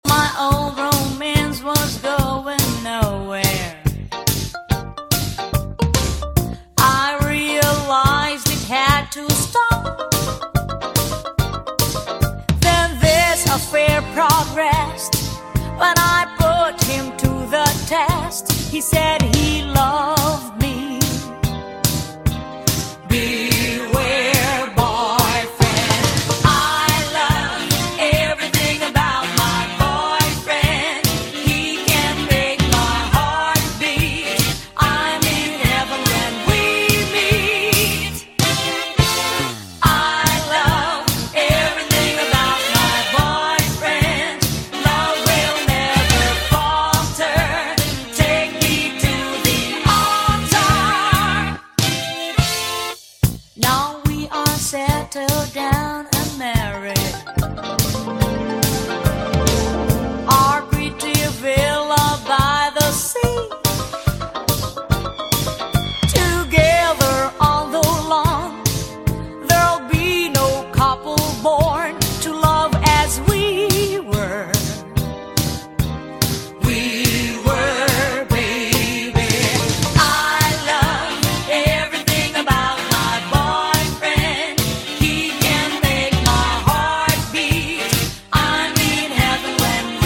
A.O.R. / CITY POP / JAPANESE DISCO BOOGIE
L.A.録音の84年作。